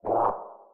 Sfx_creature_penguin_hop_voice_06.ogg